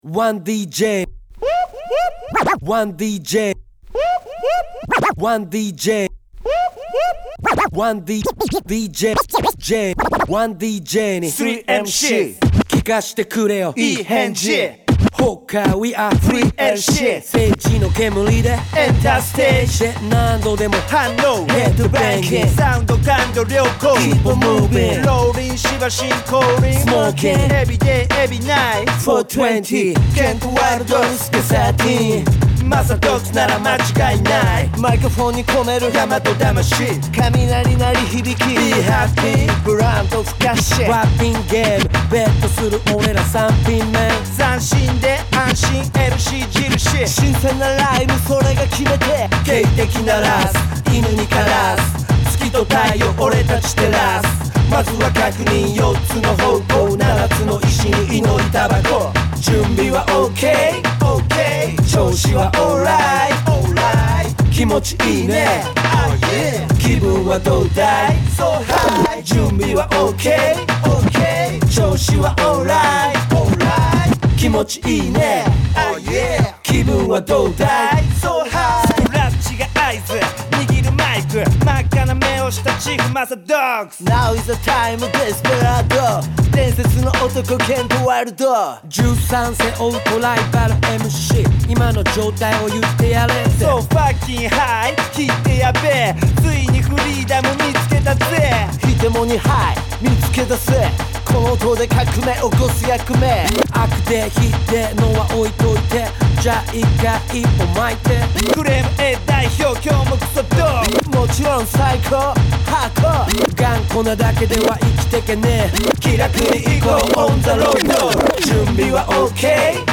＊試聴はダイジェストです。